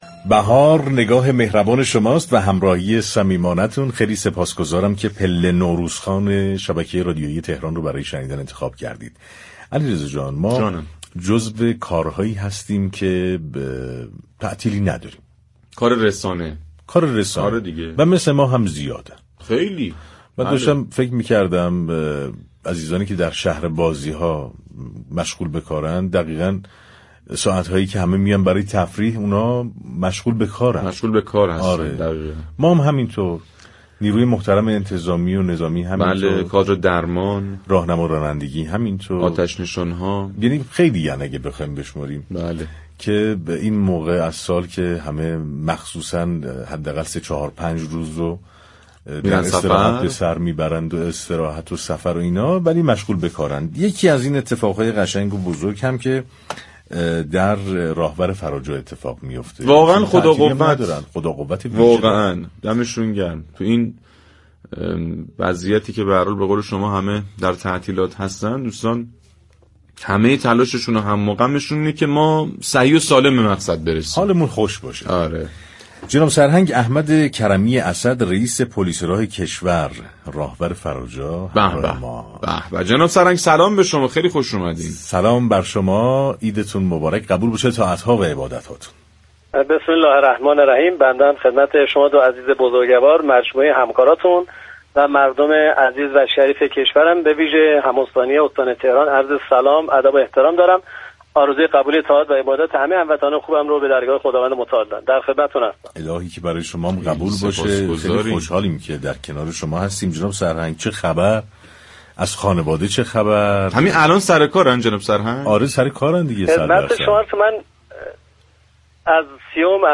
رئیس پلیس راه راهور فراجا در گفت و گو با رادیو تهران با بیان اینكه تا روز 4 فروردین، بیش از 242 نفر از هموطنانمان را در تصادفات از دست داده ایم و بیش از 7 هزار و 500 مصدوم داریم اظهار داشت: نه به تصادف یعنی نه به تخلف.